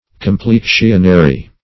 Meaning of complexionary. complexionary synonyms, pronunciation, spelling and more from Free Dictionary.
Search Result for " complexionary" : The Collaborative International Dictionary of English v.0.48: Complexionary \Com*plex"ion*a*ry\, a. Pertaining to the complexion, or to the care of it.